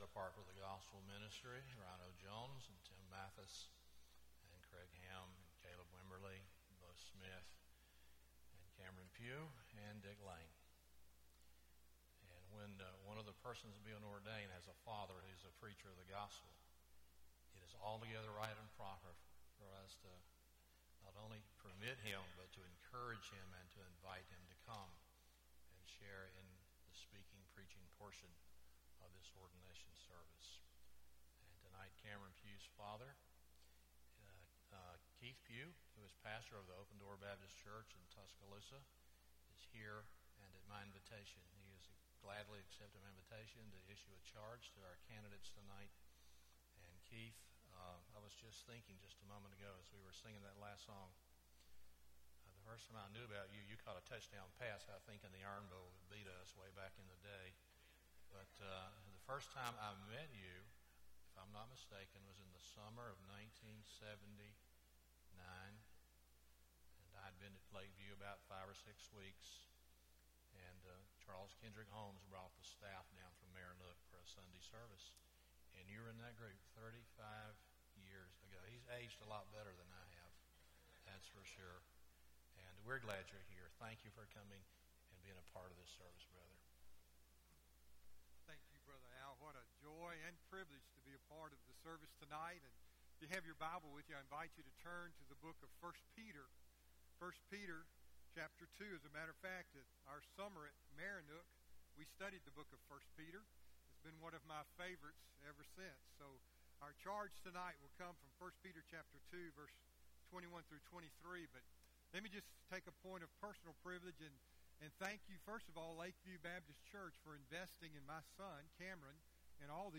Guest Speaker
(Intern Ordination)
Sermon